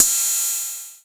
Index of /90_sSampleCDs/AKAI S6000 CD-ROM - Volume 3/Crash_Cymbal1/FX_CYMBAL